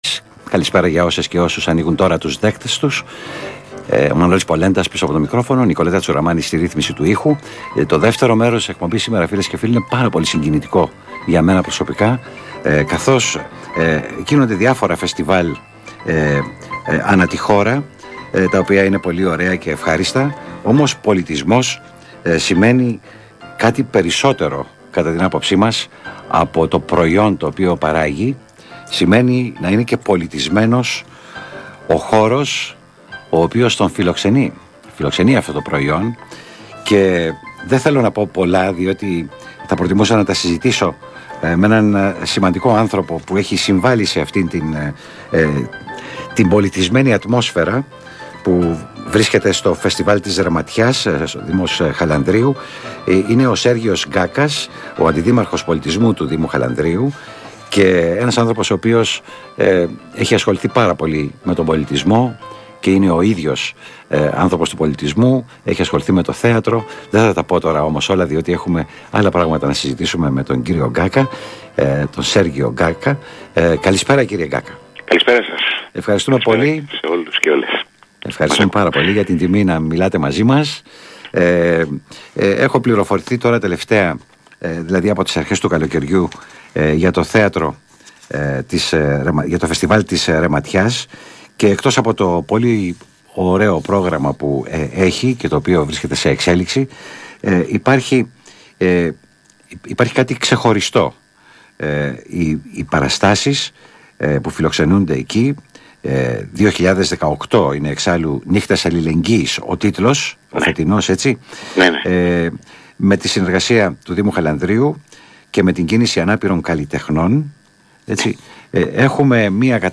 Συνέντευξη του αντιδημάρχου πολιτισμού στο κόκκινο και στην εκπομπή πολιτιστικές παράλληλοι.
Ο αντιδήμαρχος Πολιτισμού, Σέργιος Γκάκας, μιλάει Στο Κόκκινο 105,5FM, στην εκπομπή Πολιτιστικές Παράλληλοι, για τις εκδηλώσεις με προσβάσιμο περιεχόμενο στο Φεστιβάλ Ρεματιάς 2018.